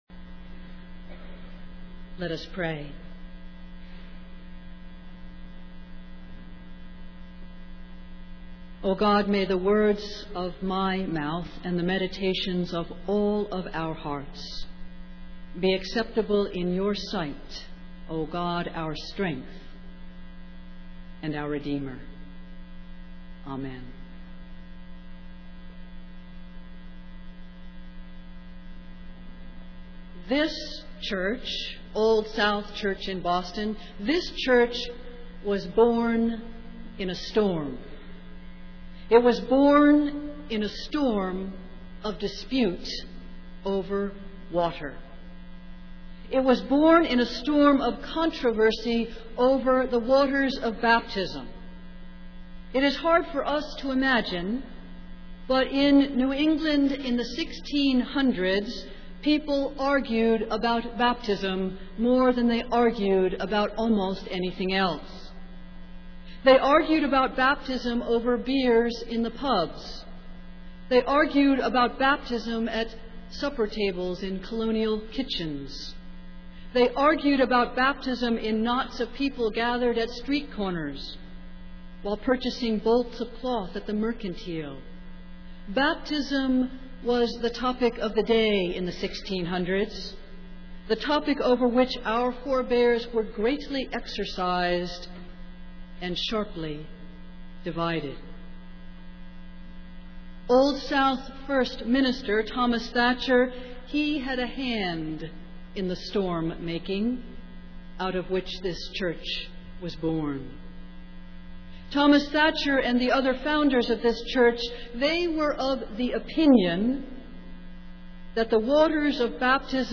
Festival Worship